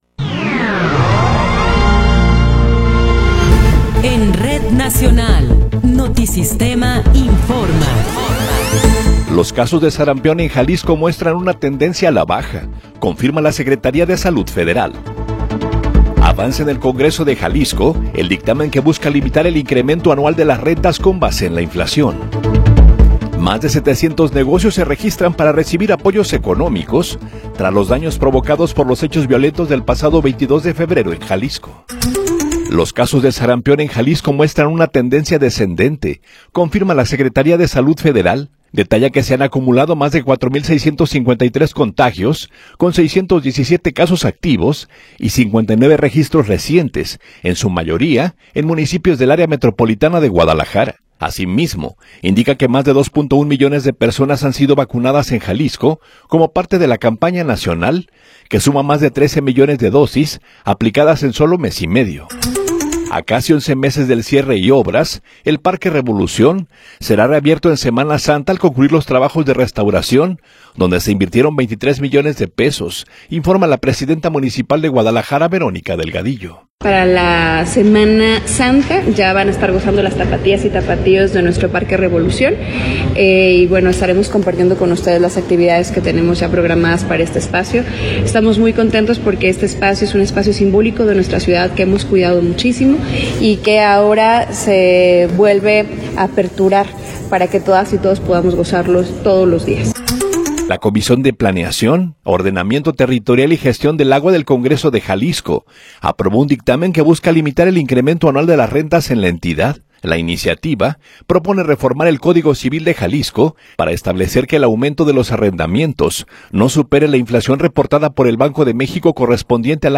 Noticiero 9 hrs. – 18 de Marzo de 2026
Resumen informativo Notisistema, la mejor y más completa información cada hora en la hora.